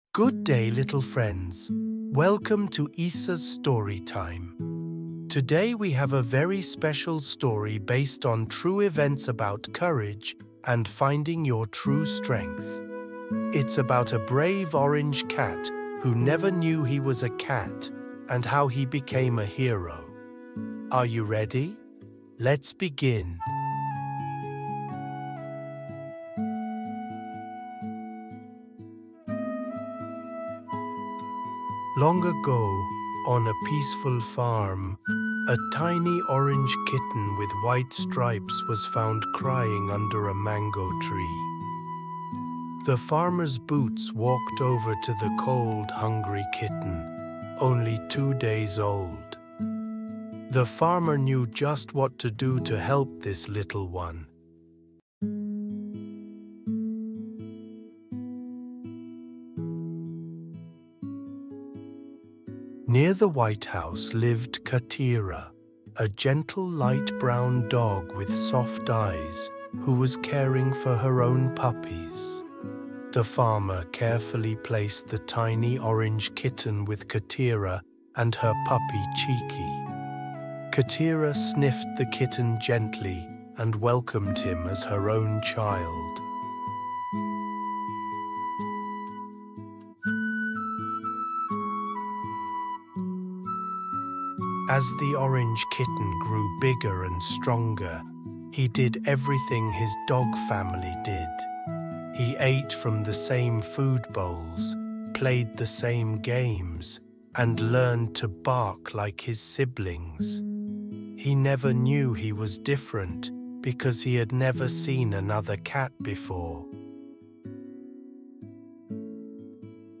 Cuento en Audio